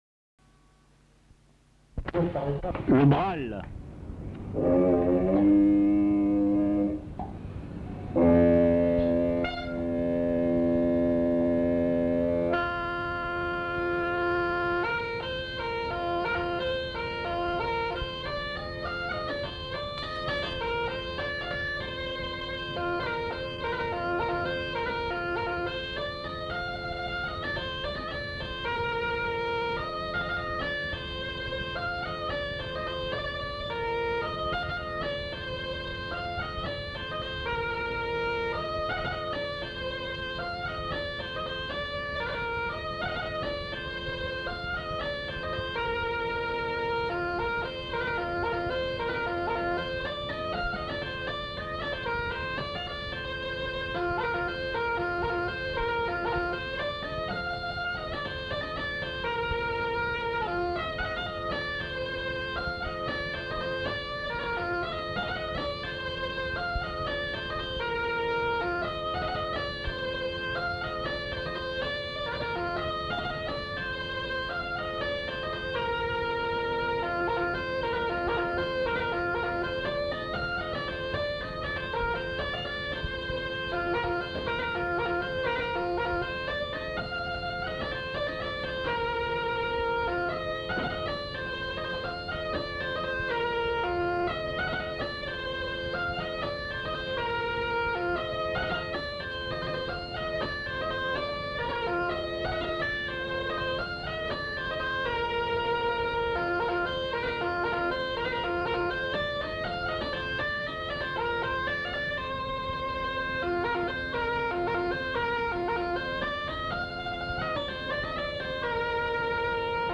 Airs de chansons et de danses interprétés à la bodega
enquêtes sonores
Farandoles